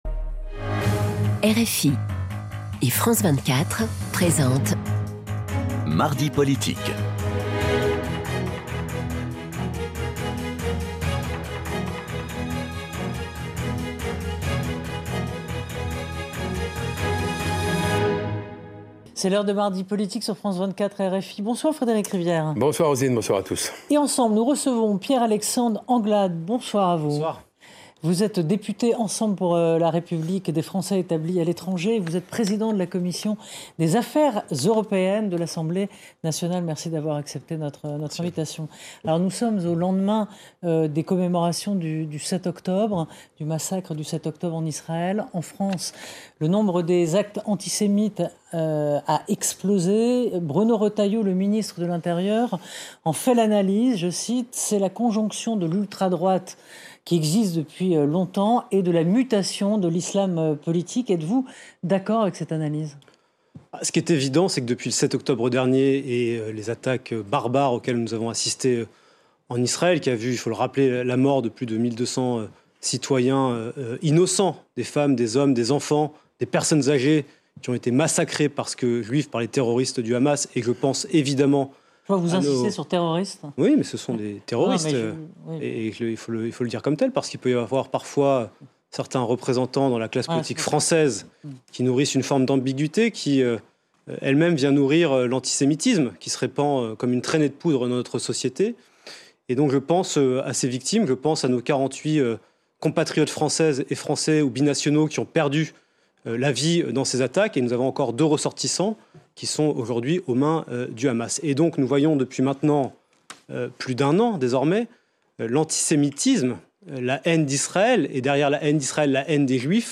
Pieyre-Alexandre Anglade est interrogé par :